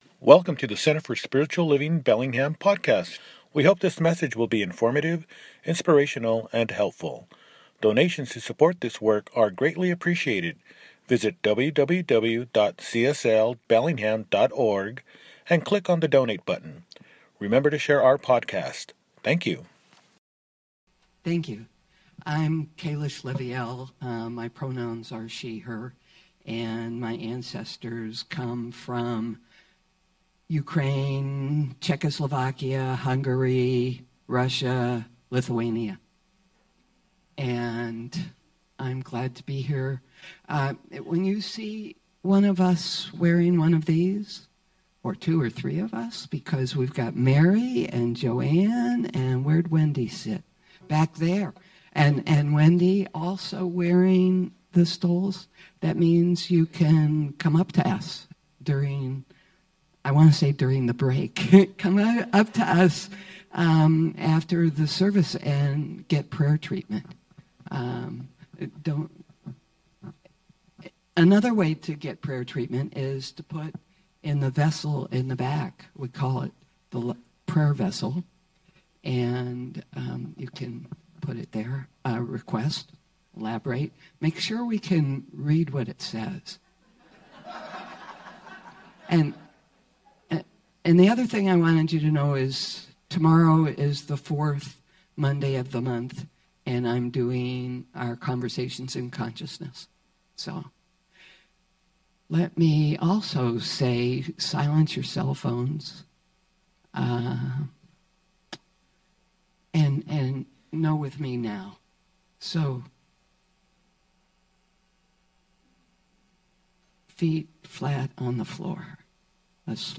Outer Collective_ On the Edge of the Possible _ Celebration Service